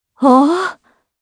Chrisha-Vox_Happy4_jp.wav